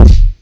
KICK - ANTI.wav